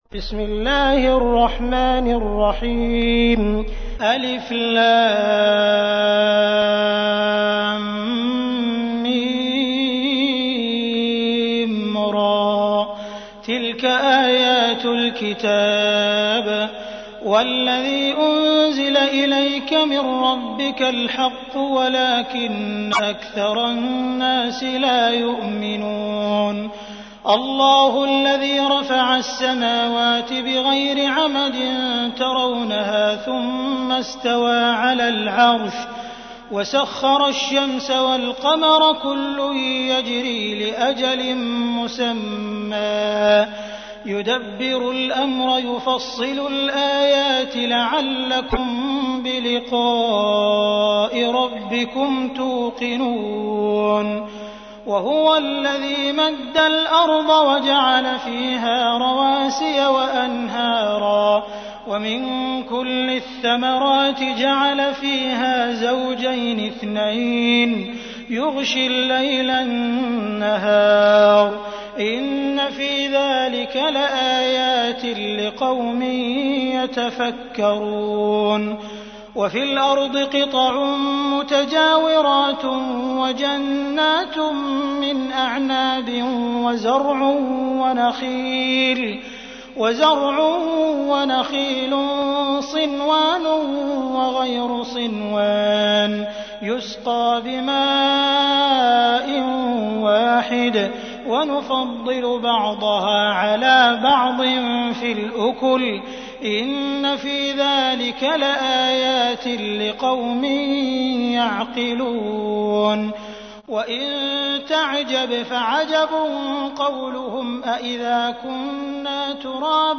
تحميل : 13. سورة الرعد / القارئ عبد الرحمن السديس / القرآن الكريم / موقع يا حسين